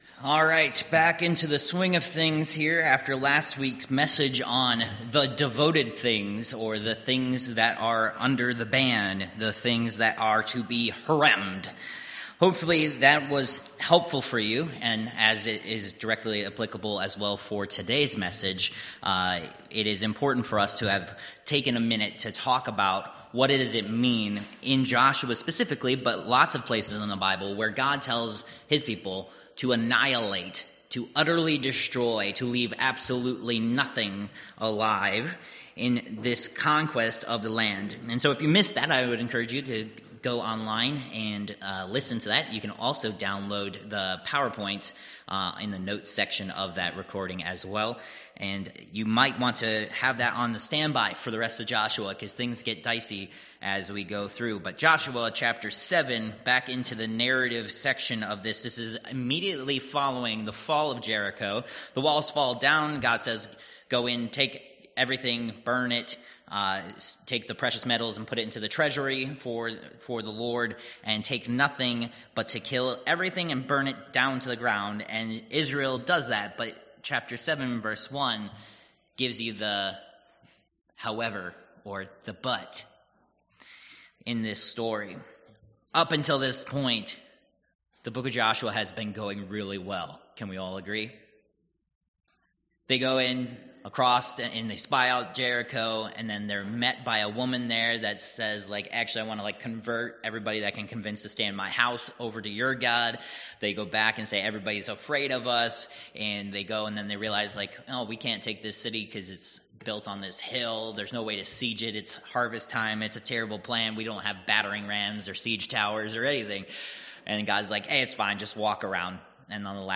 Josh. 7:1-26 Service Type: Worship Service « Protected